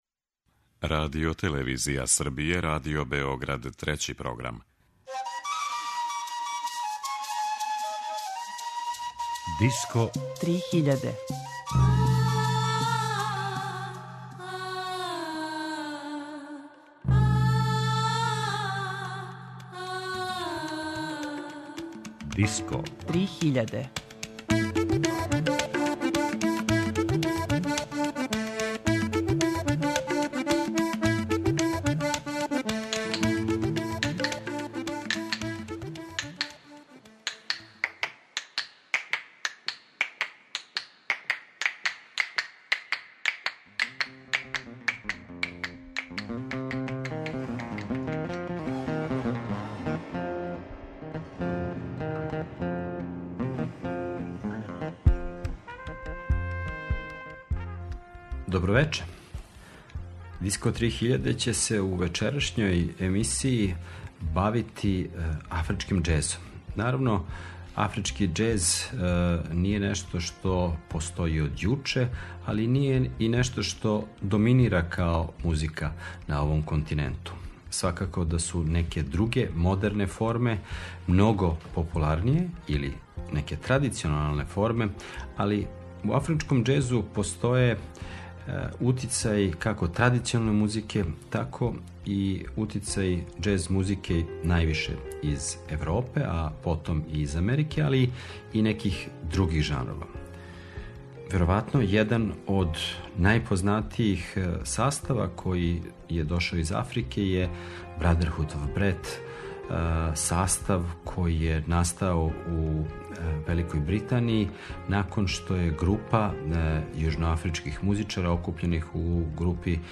Афрички џез